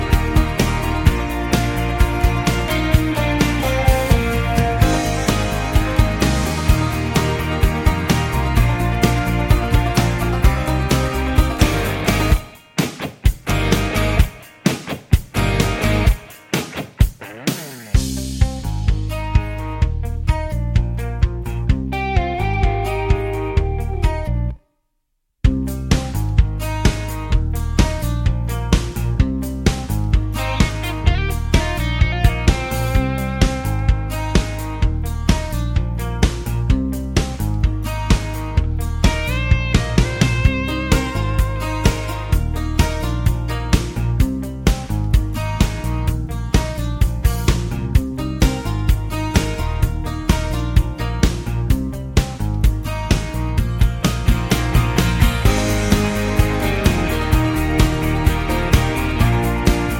no Backing Vocals Country (Male) 4:33 Buy £1.50